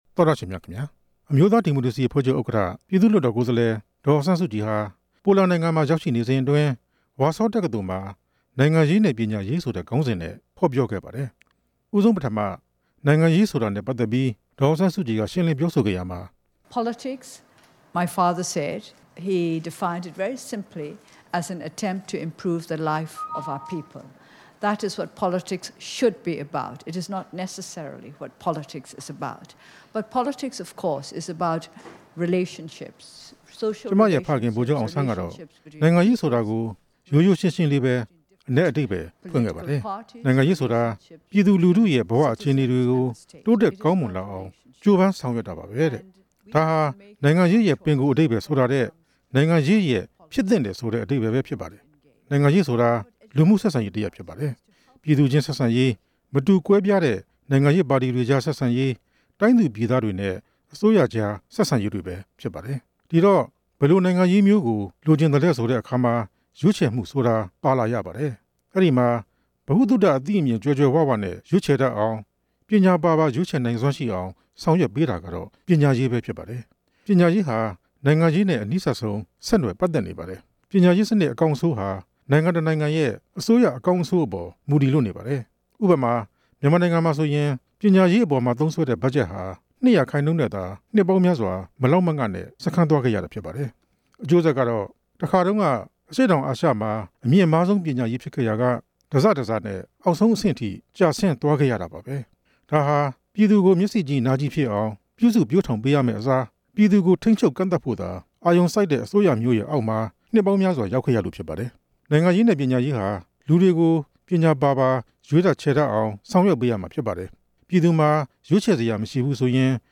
ဒေါ်အောင်ဆန်းစုကြည်ရဲ့ ဝါဆောတက္ကသိုလ် မိန့်ခွန်း